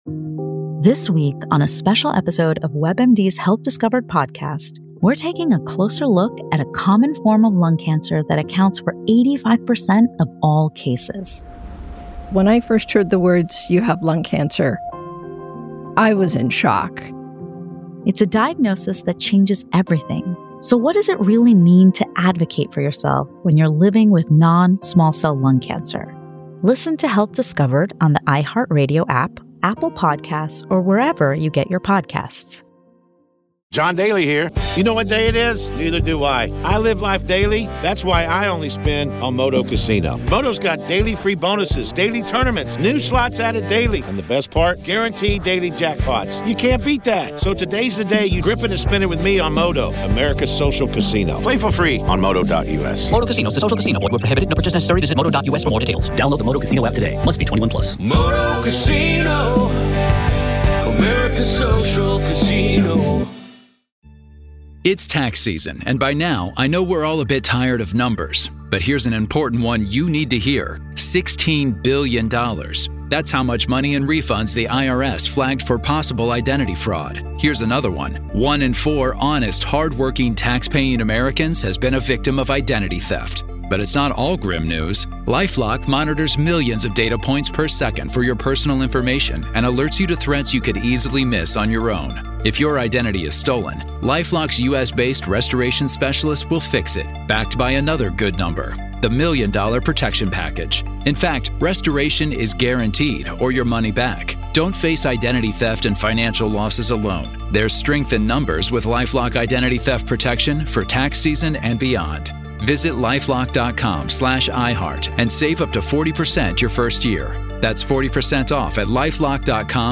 True Crime Today | Daily True Crime News & Interviews / Could Lori Vallow Daybell's Murder Spree Been Interrupted?